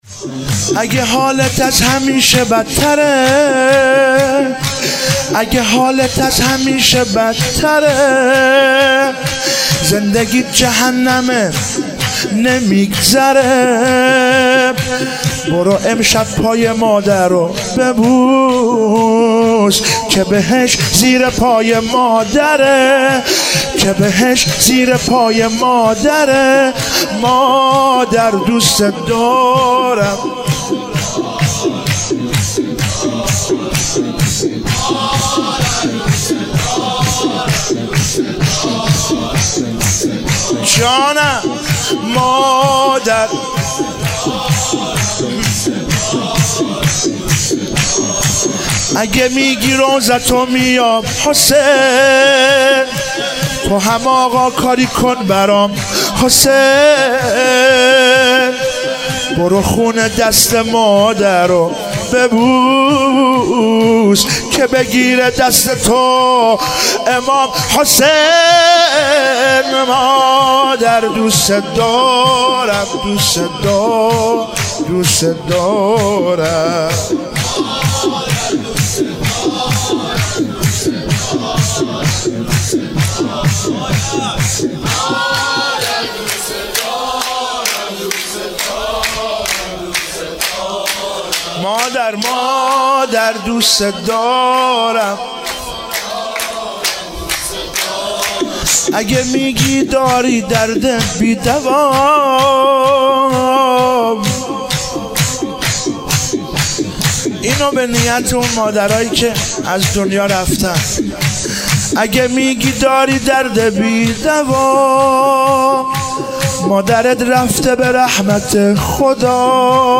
ولادت حضرت زهرا سلام الله علیها96 - شور - اگه حالت از همیشه بدتره